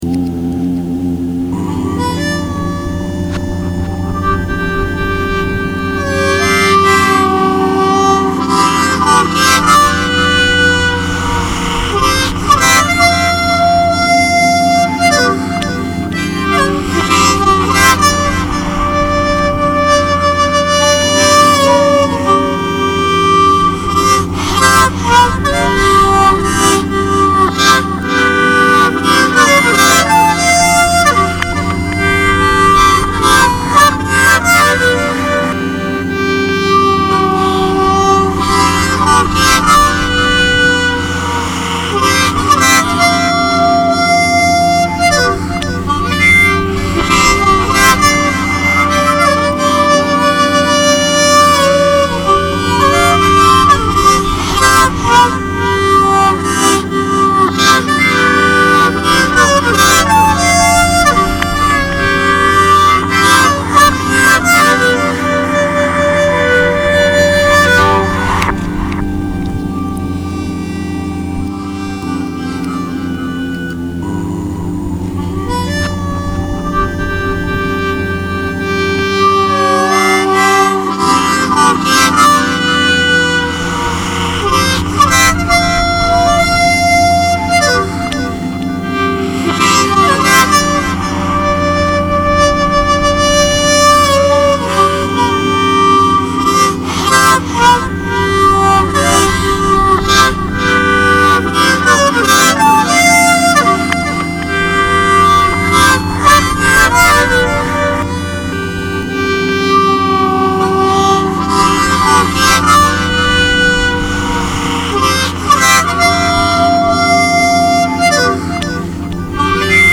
sound sculpture